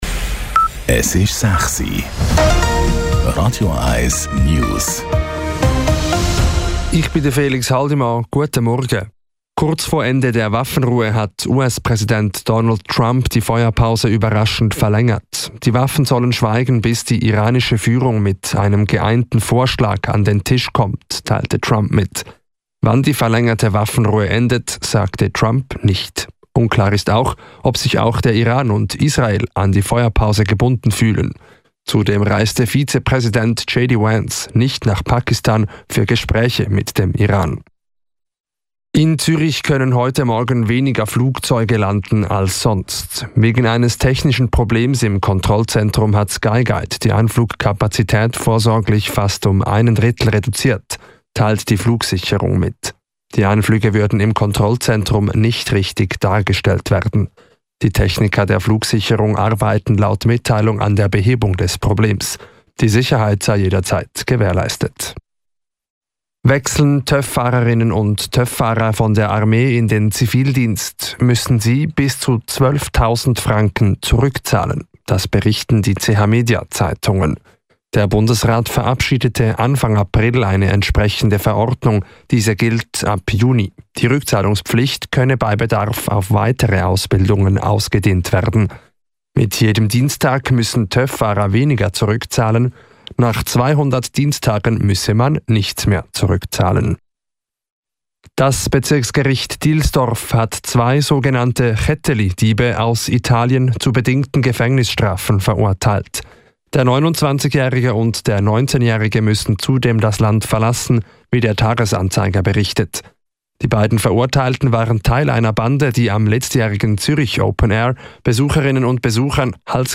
Radio 1 News vom 22.04.2026 06:00